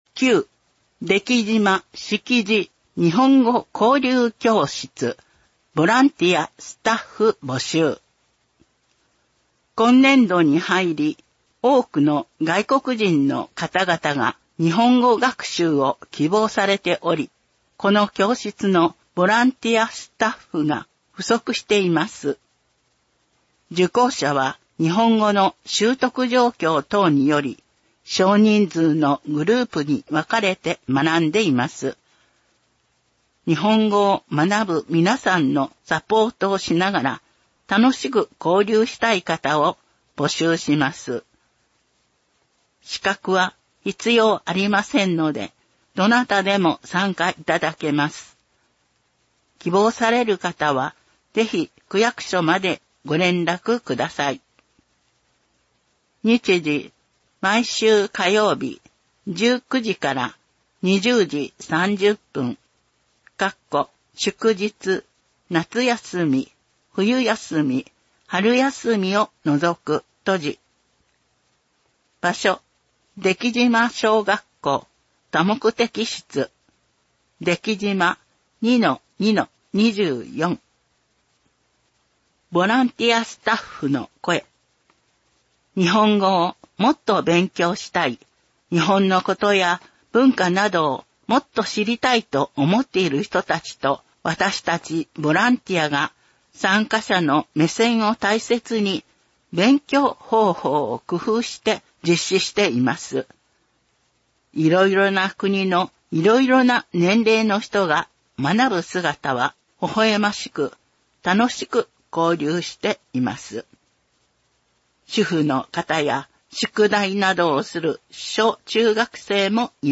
西淀川区ボランティア・市民活動センター登録の音訳グループ「やまびこ」さんのご協力により音訳CD版（デイジー図書）が毎月発行されています。